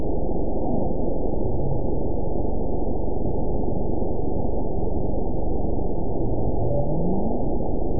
event 912425 date 03/26/22 time 15:47:25 GMT (3 years, 1 month ago) score 9.53 location TSS-AB05 detected by nrw target species NRW annotations +NRW Spectrogram: Frequency (kHz) vs. Time (s) audio not available .wav